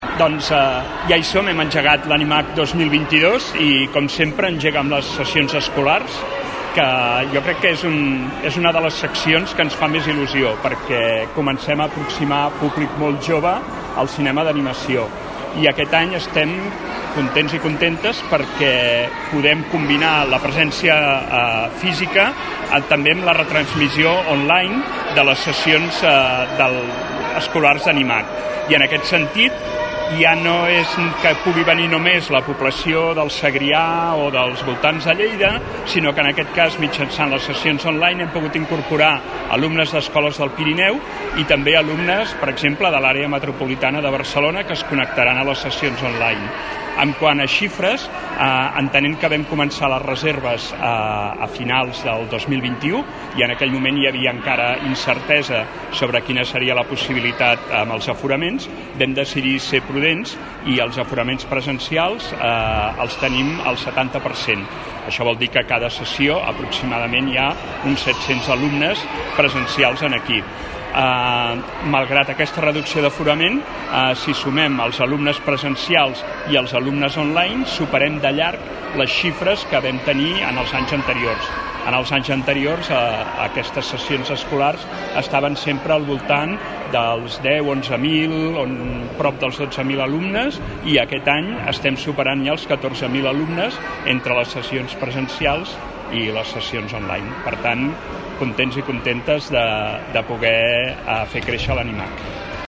tall-de-veu-del-regidor-jaume-rutllant-sobre-linici-de-les-sessions-escolars-danimac-2022